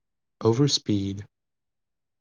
overspeed.wav